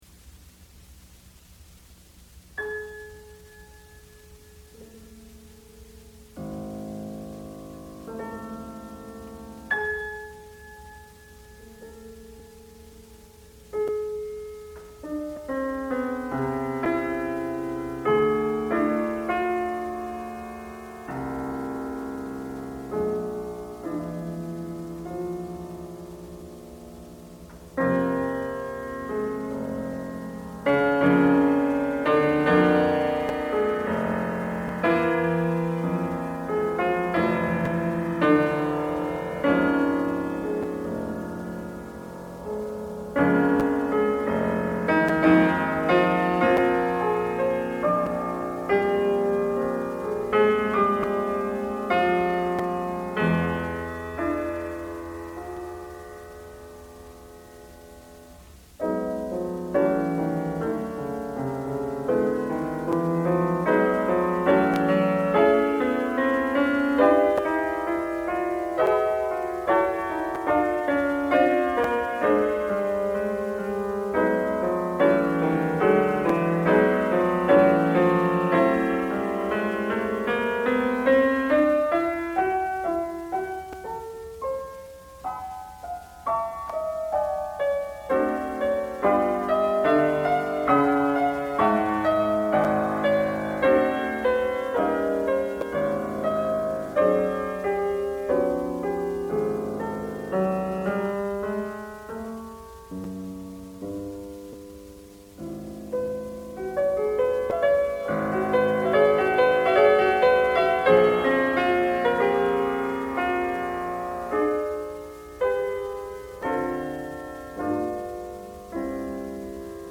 Pianist 5:09 Debussy Etude 10 (Live Recital, NYC